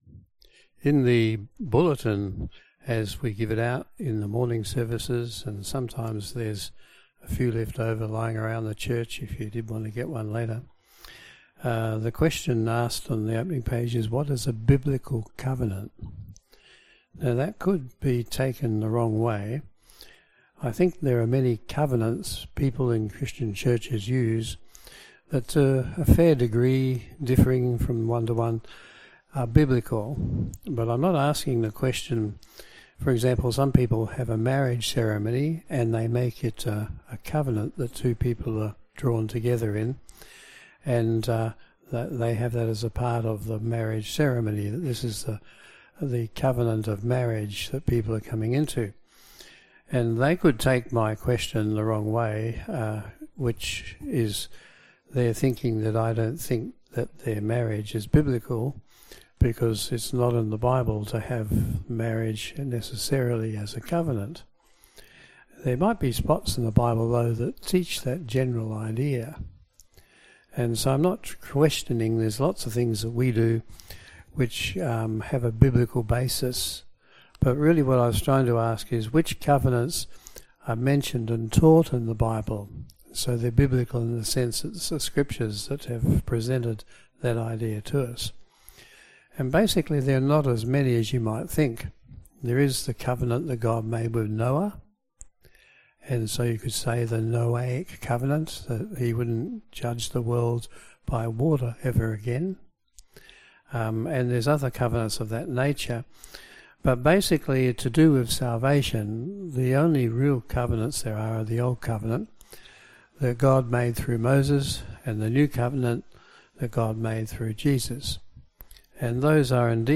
Service Type: PM Service